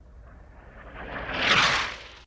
Звук летящего минометного снаряда (mortar shell)